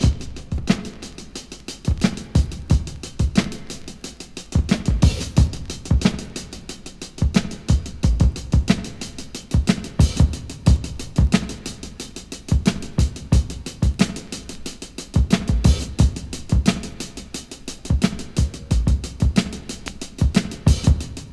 • 90 Bpm Breakbeat Sample C# Key.wav
Free breakbeat sample - kick tuned to the C# note. Loudest frequency: 1006Hz
90-bpm-breakbeat-sample-c-sharp-key-LJe.wav